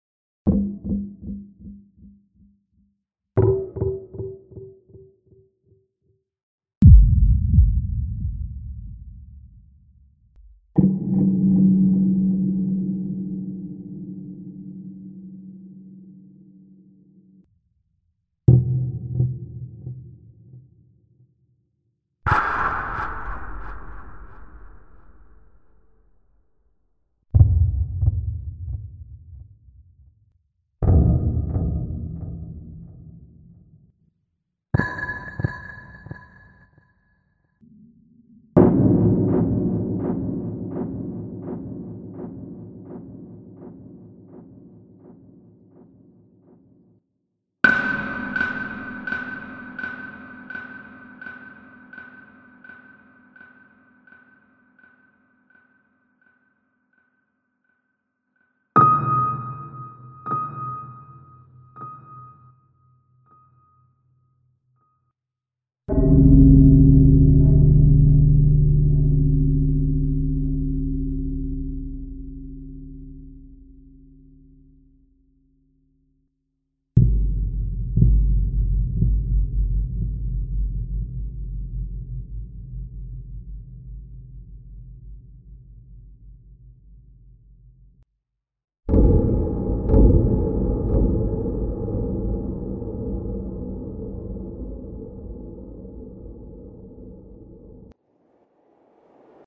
Звуки эха
Удары с отголосками (сборник)